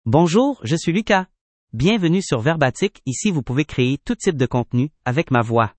MaleFrench (Canada)
LucasMale French AI voice
Lucas is a male AI voice for French (Canada).
Voice sample
Lucas delivers clear pronunciation with authentic Canada French intonation, making your content sound professionally produced.